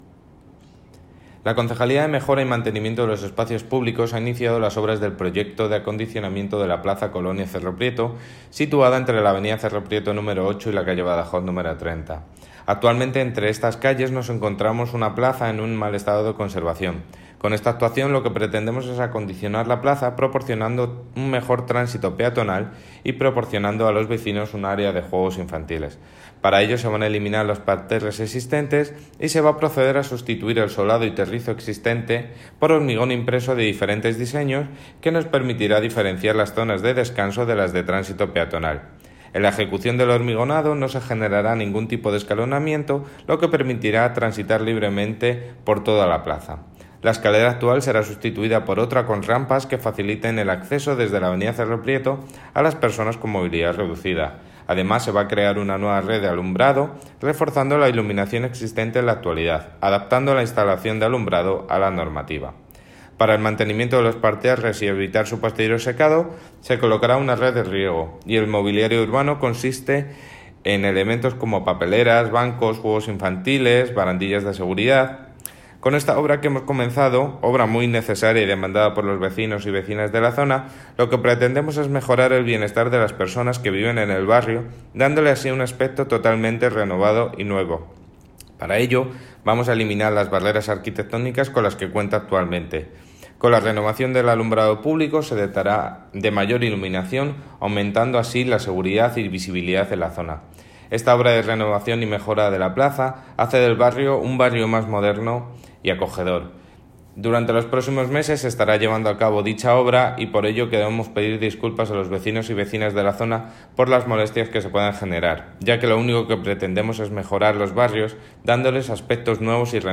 David Muñoz, concejal de Mejora y Mantenimiento de los Espacios Públicos en Plaza Colonia Cerro Prieto